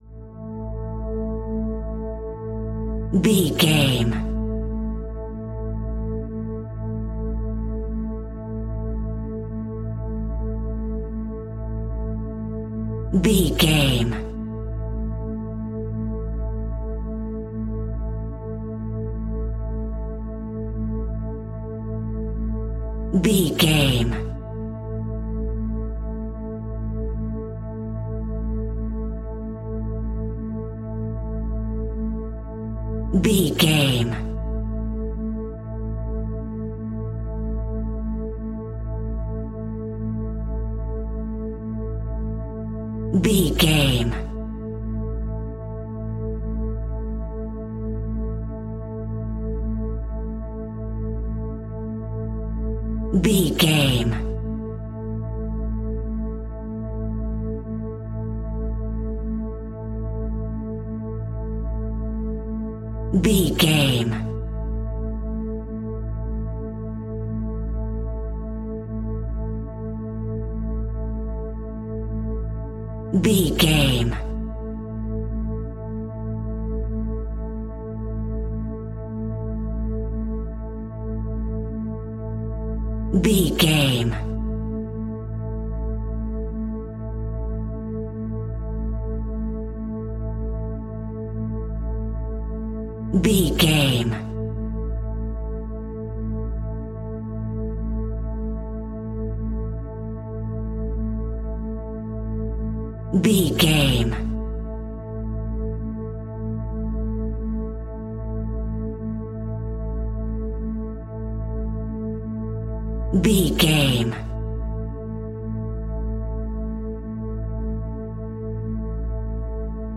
Aeolian/Minor
Slow
scary
tension
ominous
dark
suspense
eerie
synthesiser
horror
Synth Pads
Synth Strings
synth bass